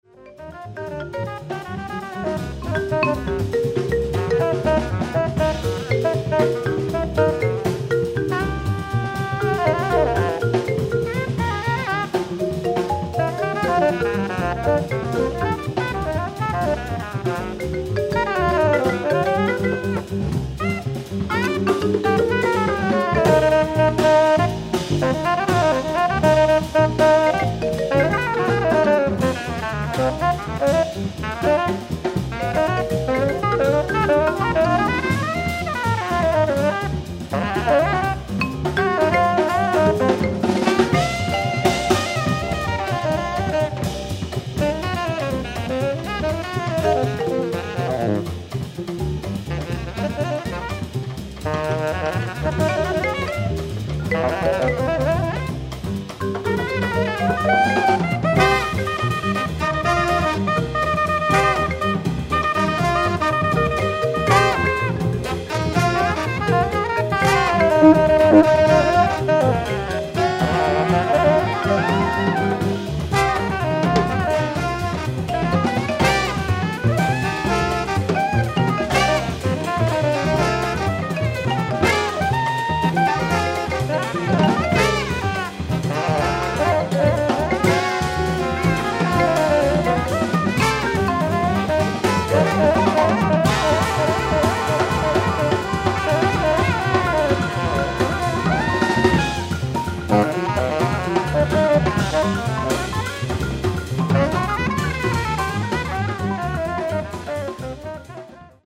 ライブ・アット・アウトドアー・フェステバル、ヴァンクーバー、ワシントン 10/22/1999
オフィシャル級のクオリティー！！
※試聴用に実際より音質を落としています。